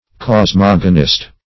Search Result for " cosmogonist" : The Collaborative International Dictionary of English v.0.48: Cosmogonist \Cos*mog"o*nist\ (k?z-m?g"?-n?st), n. One who treats of the origin of the universe; one versed in cosmogony.
cosmogonist.mp3